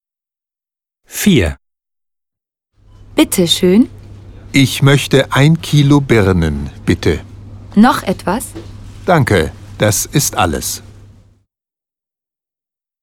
Dialog 4: